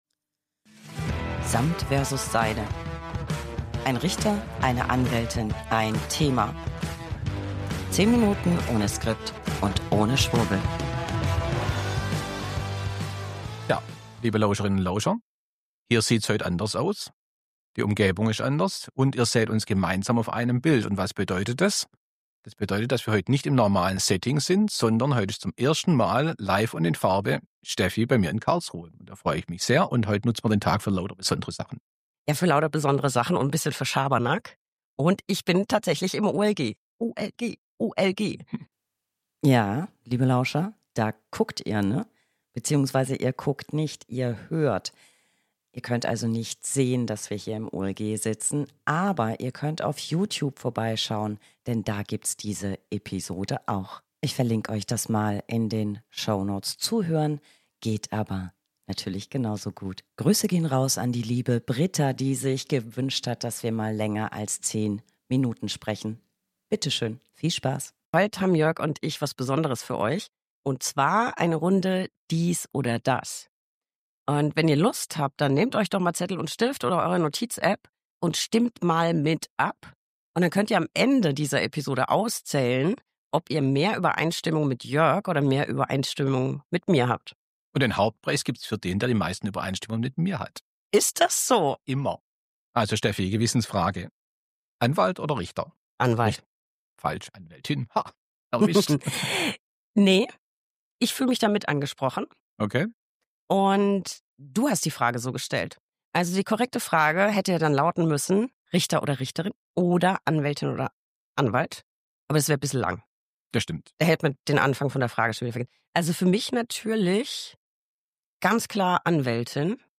Beschreibung vor 7 Monaten 1 Anwältin + 1 Richter + 1 Thema. 10 Minuten ohne Skript und ohne Schwurbel.